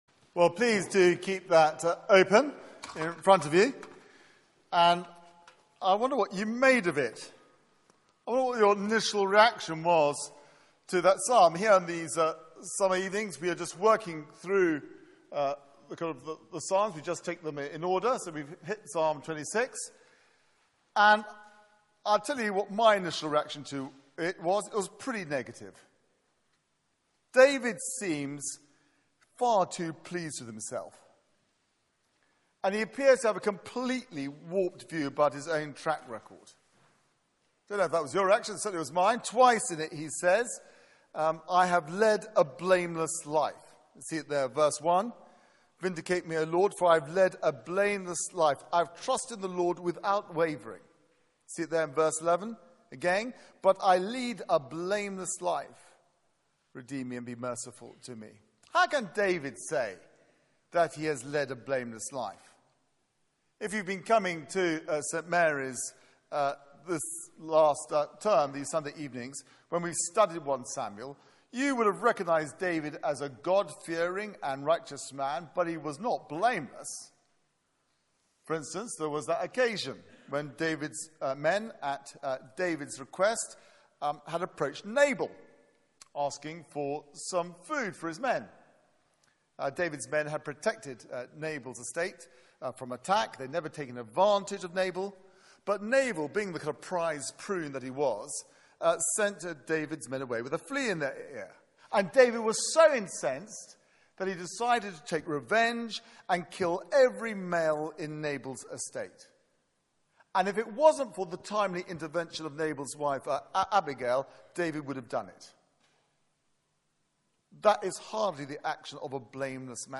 Media for 6:30pm Service on Sun 10th Aug 2014 18:30
Series: Summer Songs Theme: 'My feet stand on level ground' Sermon